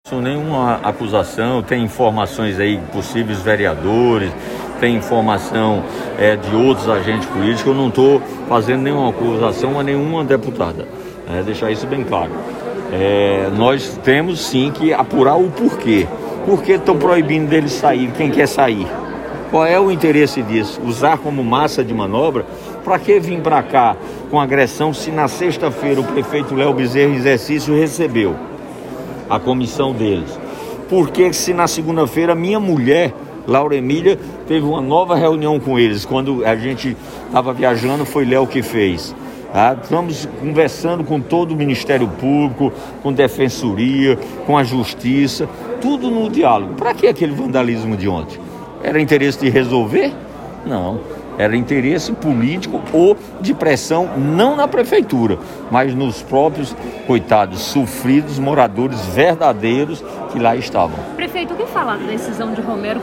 O prefeito de João Pessoa, Cícero Lucena, insinuou nesta quarta-feira (15), durante solenidade em que apresentou ações e investimentos na área da habitação para a Capital, que a invasão ocorrida ontem por moradores da Comunidade Dubai na sede da PMJP, teria tido por trás, “interesses políticos “.
Abaixo o áudio da declaração do prefeito Cícero Lucena.